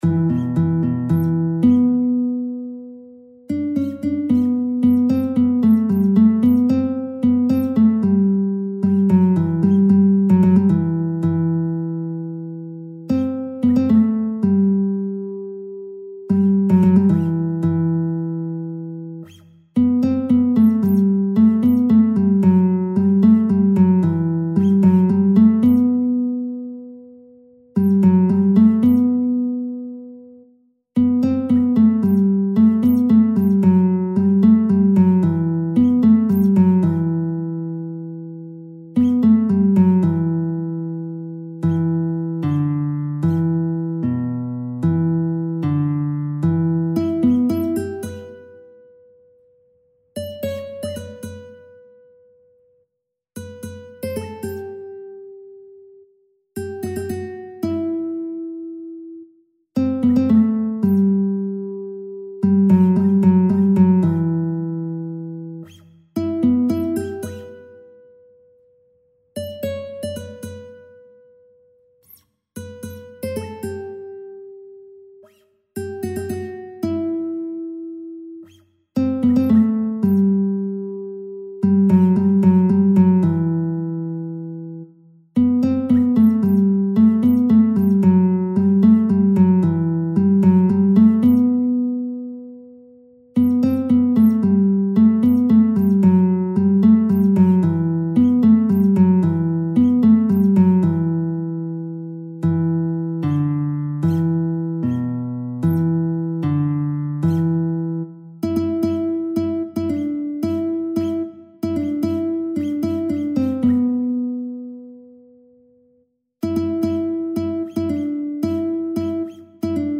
جهت اجرا با گیتار تنظیم شده است